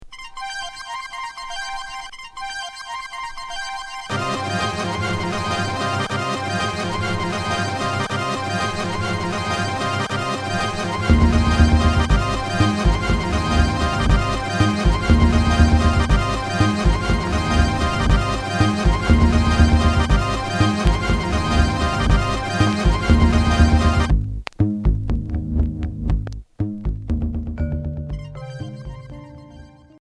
Ambiant synthétique